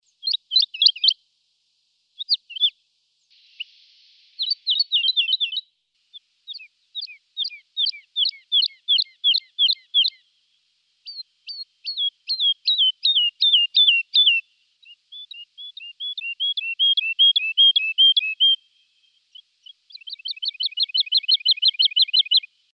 На этой странице вы найдете подборку звуков жаворонка – от звонких утренних трелей до нежных переливов.